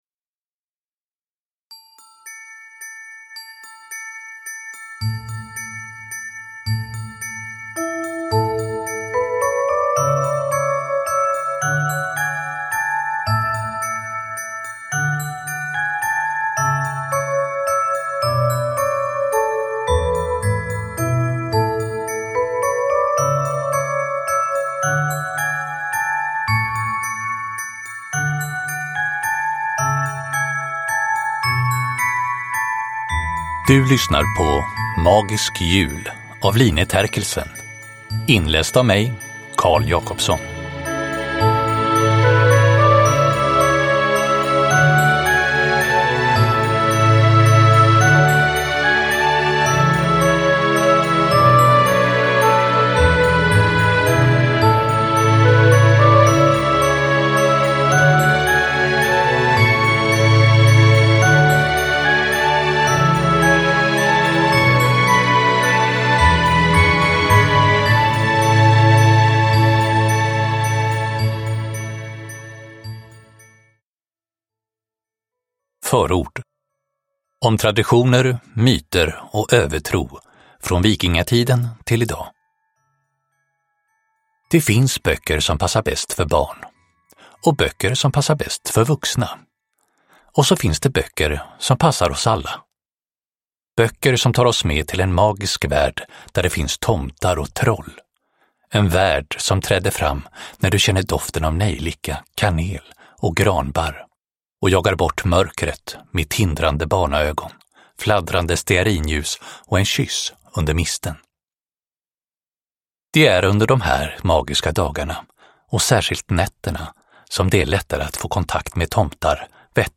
Magisk jul – Ljudbok – Laddas ner